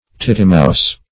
tittimouse - definition of tittimouse - synonyms, pronunciation, spelling from Free Dictionary Search Result for " tittimouse" : The Collaborative International Dictionary of English v.0.48: Tittimouse \Tit"ti*mouse`\, n. (Zool.) Titmouse.